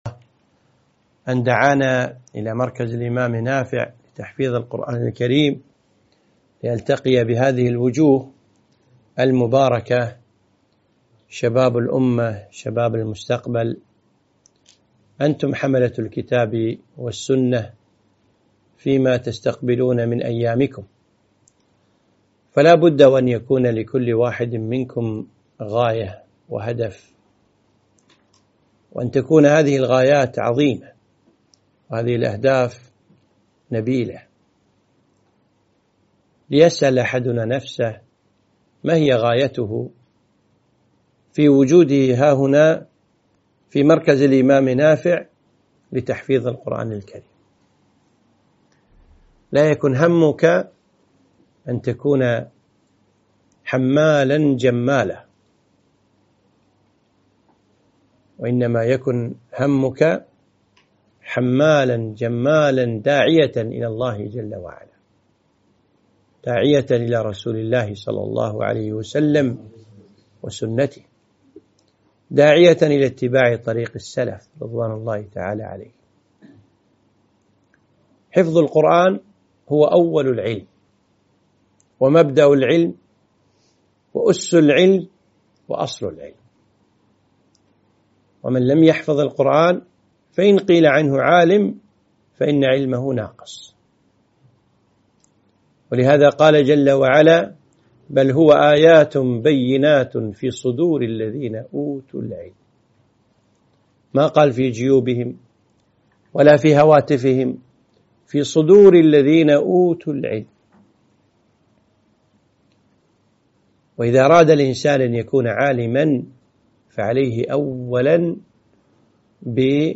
محاضرة - شخصية طالب العلم